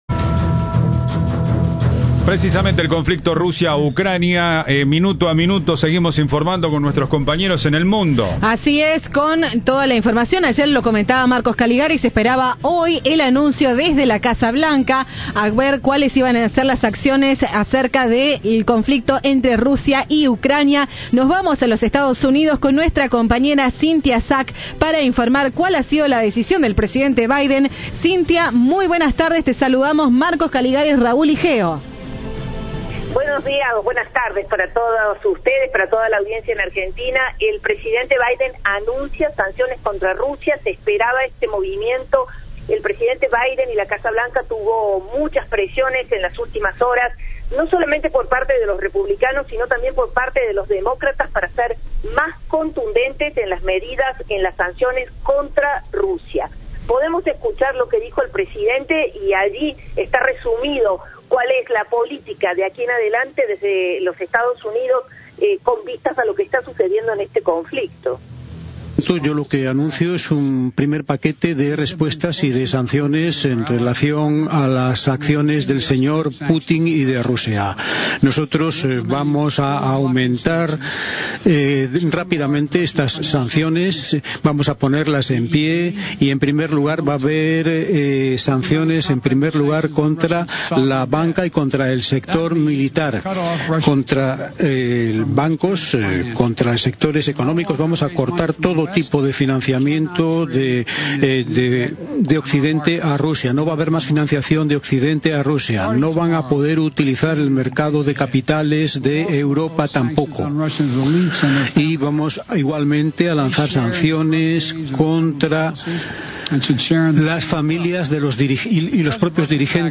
"Si Rusia va más allá con esta invasión, iremos más allá con las sanciones", añadió el demócrata al leer un comunicado desde la Casa Blanca, sin aceptar preguntas de los periodistas.